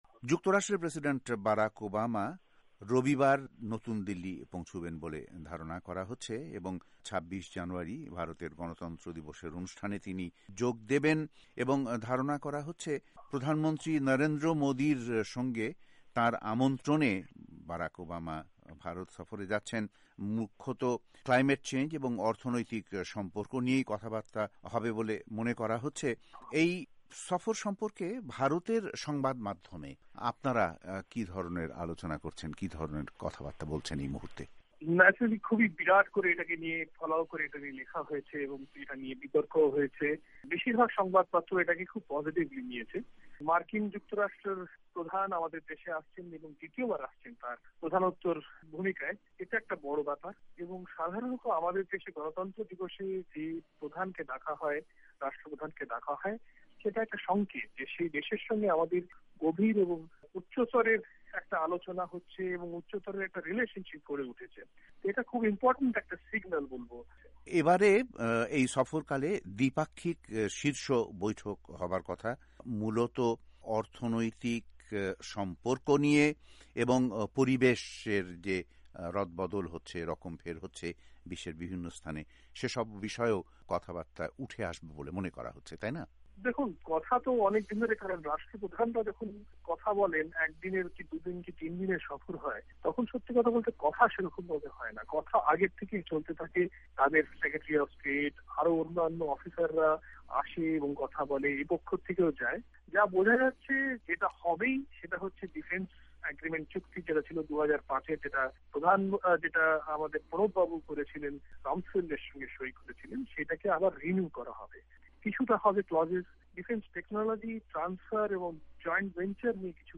প্রেসিডেন্ট ওবামার ভারত সফর নিয়ে ভয়েস অফ এ্যামেরিকার সাক্ষাত্কার রিপোর্ট